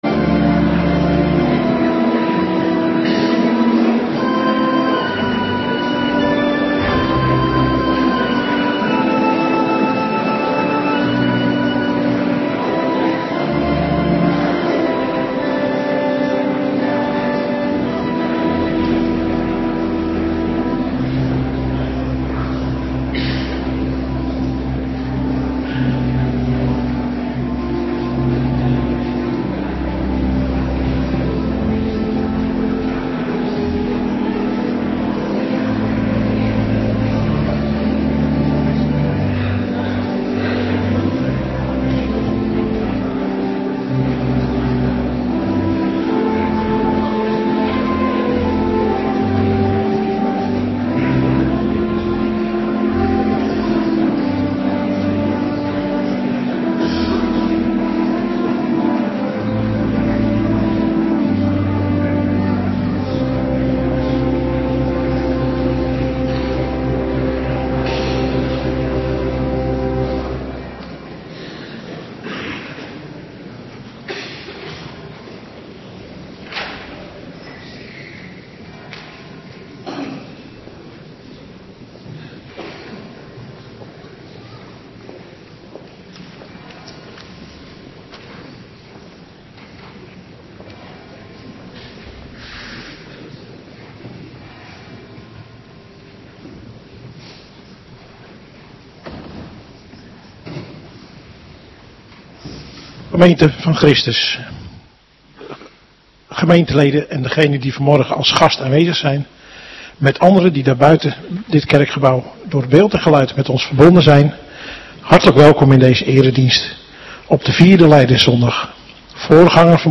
Morgendienst 8 maart 2026